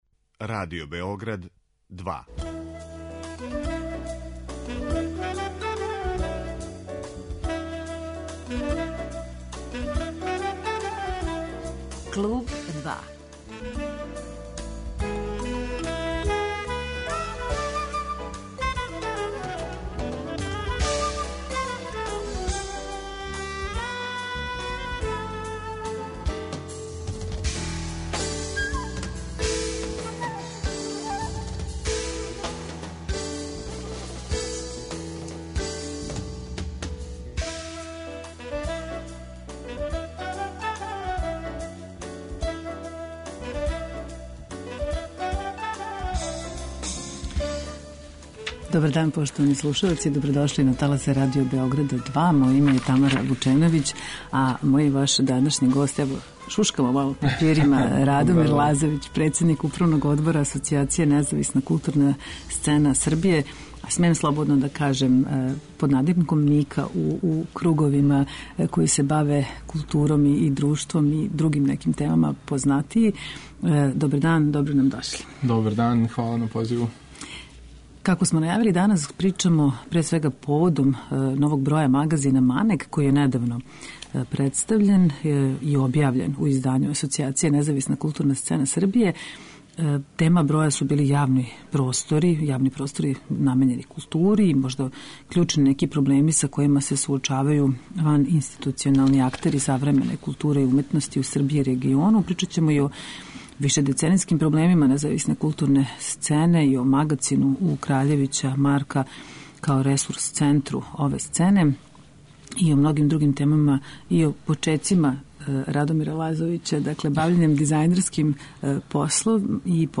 Гост 'Клуба 2' је Радомир Лазовић, председник Управног одбора Асоцијације НКСС